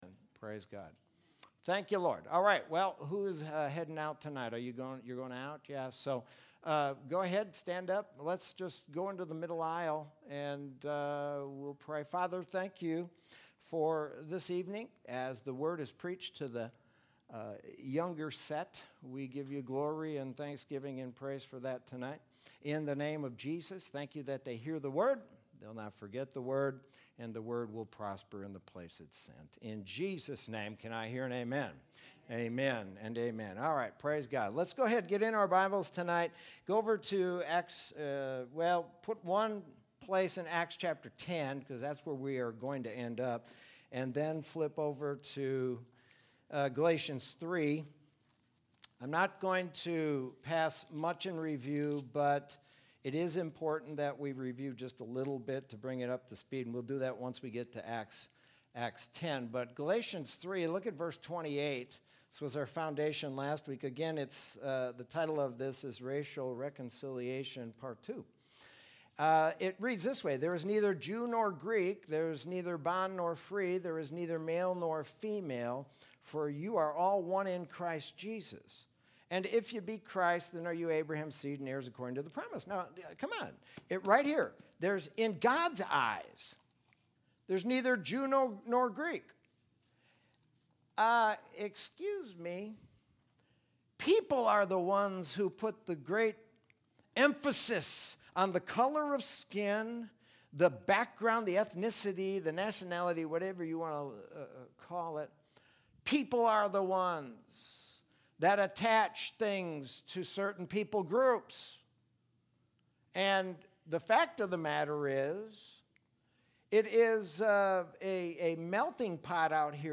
Sermon from Wednesday, July 15, 2020.